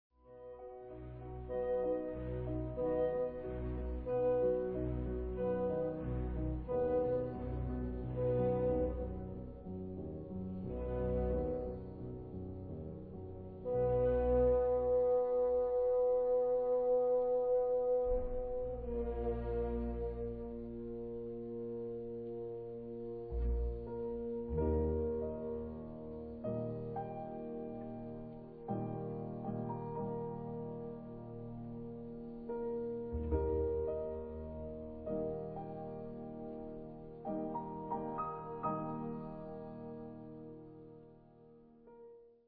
Beispiel: Rückung
Durch die lange H-Dur-Passage und den einzeln übrig bleibenden Grundton wird die harmonische Entfernung der folgenden halbtönigen Abwärtsrückung besonders unterstrichen.